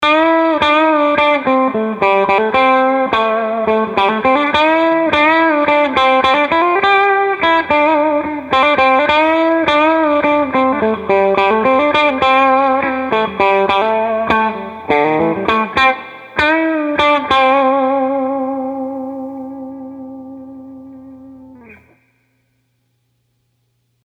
Clean – Bridge Pickup
Clean clips were played in the Clean channel of my Fender Hot Rod Deluxe, while the dirty clips were played in the Drive channel.
clean-treble.mp3